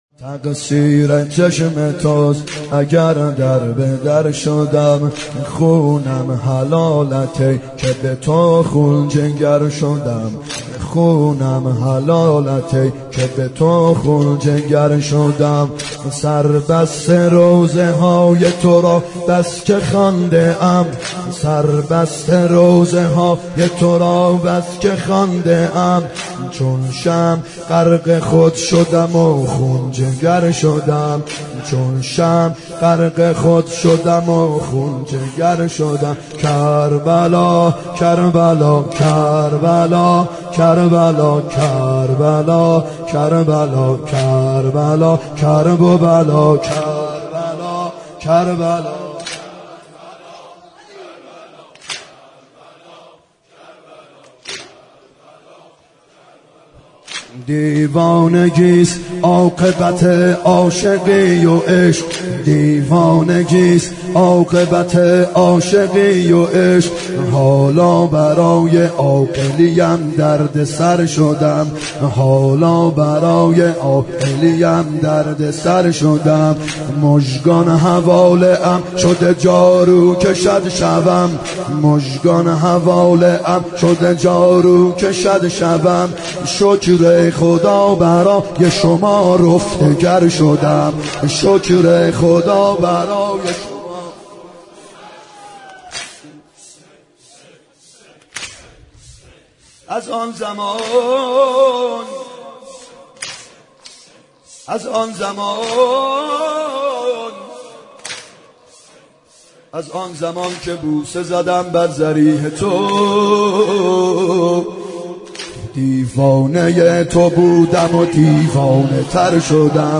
محرم 90 شب چهارم واحد ( تقصیر چشم توست اگر در به در شدم
محرم 90 ( هیأت یامهدی عج)